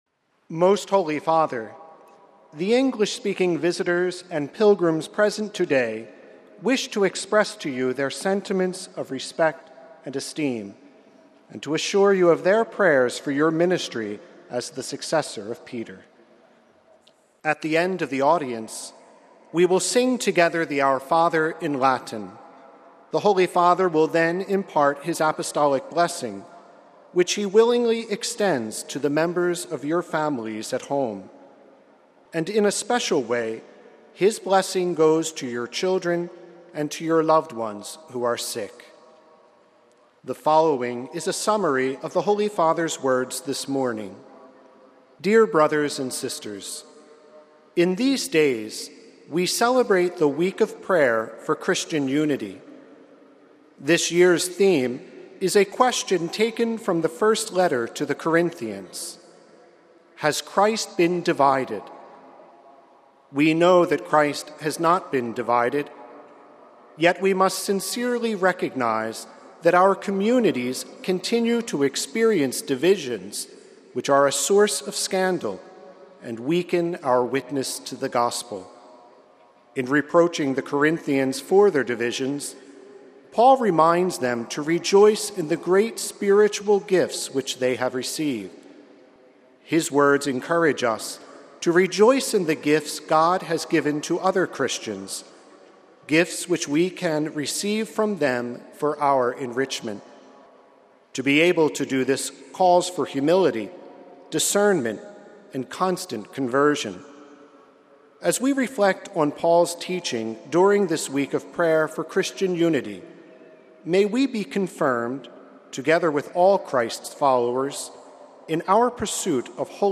The general audience of Jan. 22 was held in the open, in Rome’s St. Peter’s Square.
Basing himself on the scripture passage, Pope Francis delivered his main discourse in Italian - summaries of which were read out by aides in various languages, including in English. But first, the aide greeted the Pope on behalf of the English-speaking pilgrims.